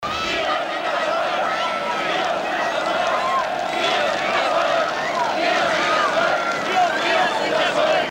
Wir sind das Volk-Rufe im Jahr 1989